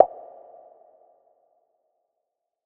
PERC - LINE.wav